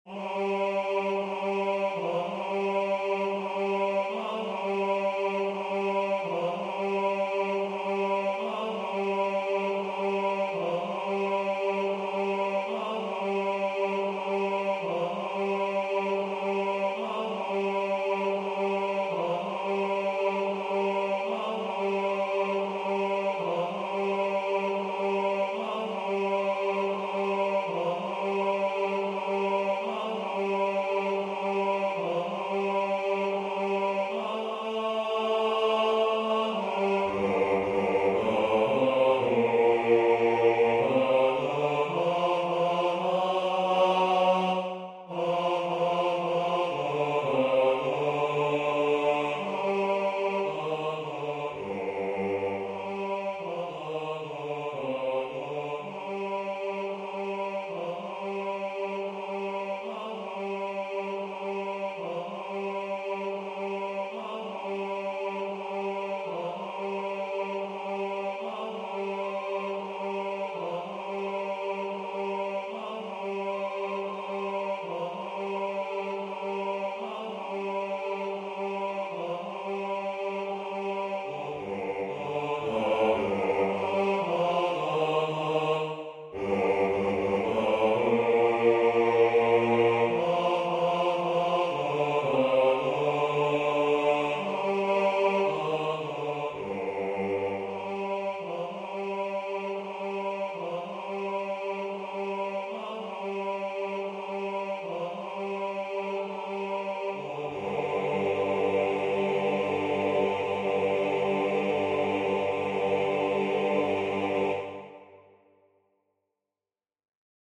Basse Voix Synth